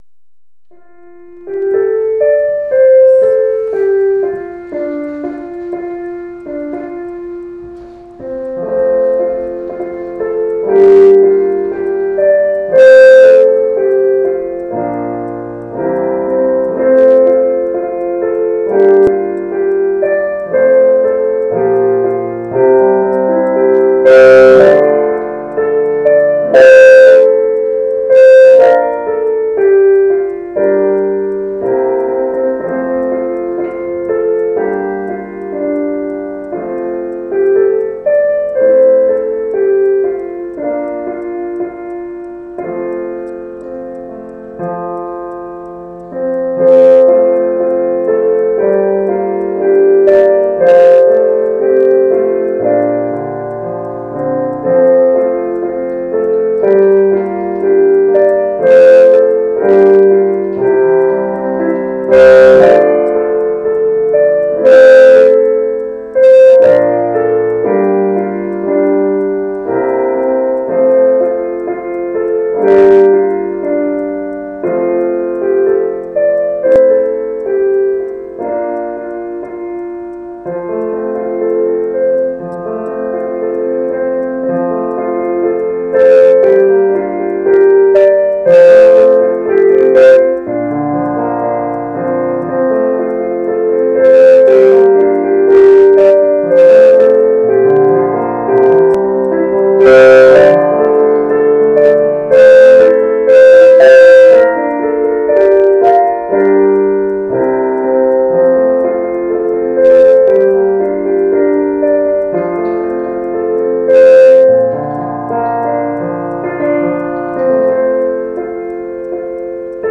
Reflections of a Lad at Sea (slow tempo, keyboard only)